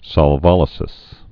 (sŏl-vŏlĭ-sĭs, sôl-)